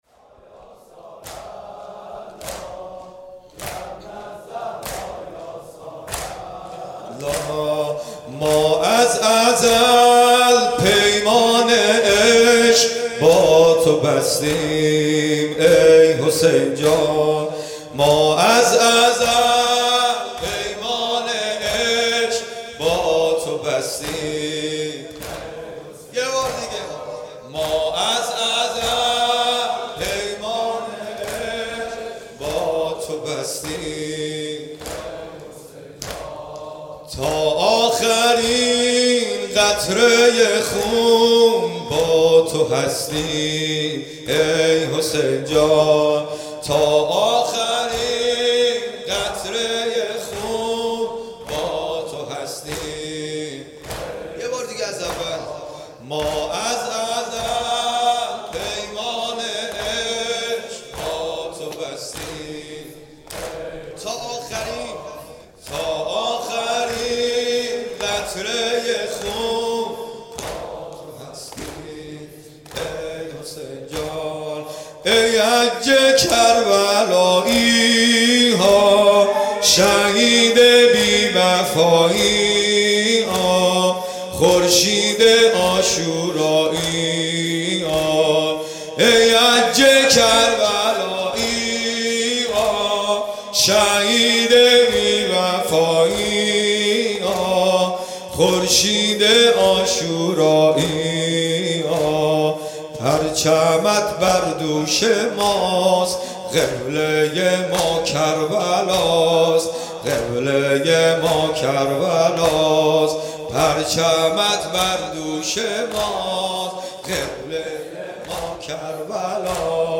• مداحی